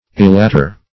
Elater - definition of Elater - synonyms, pronunciation, spelling from Free Dictionary
Elater \E*lat"er\, n.